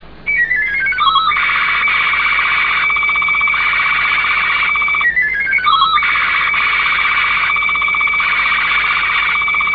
RealAudio FAX/SSTV Sounds
SSTV B/W 8 8 sec. 120/128